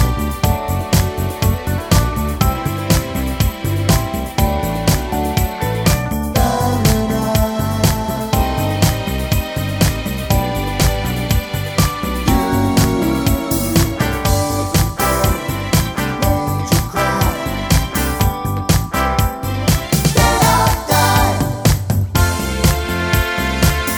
no Backing Vocals Soul / Motown 4:16 Buy £1.50